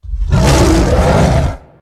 wolf_attack_2.ogg